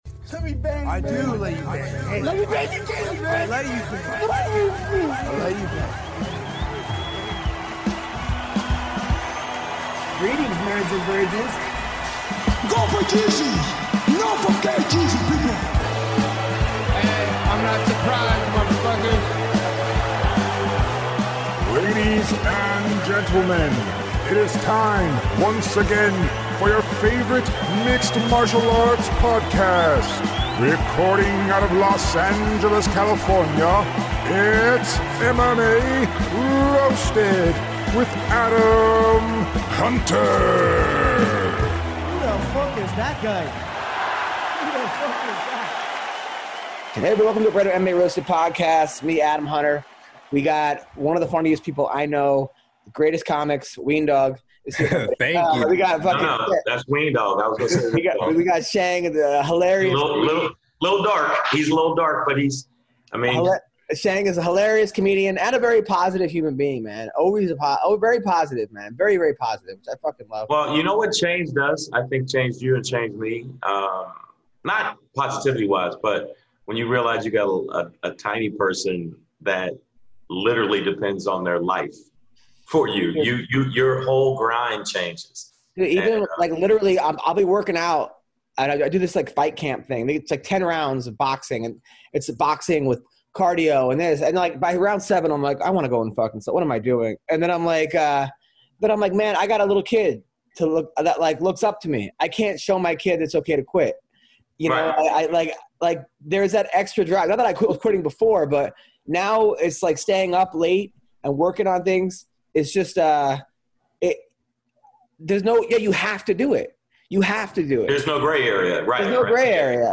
UFC veteran Gerald Meerschaert calls in to discuss his fight against Khamzat Chimaev, and UFC bantamweight Kyler Phillips calls in to give his thoughts on UFC 254.